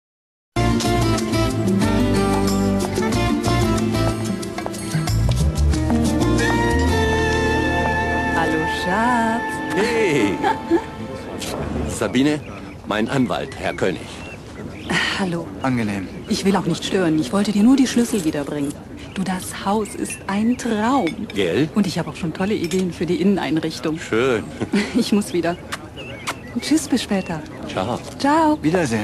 Typ: warme Stimme, sehr wandlungsfähig: von seriös, frisch bis sexy.
Profi-Sprecherin.
Sprechprobe: Werbung (Muttersprache):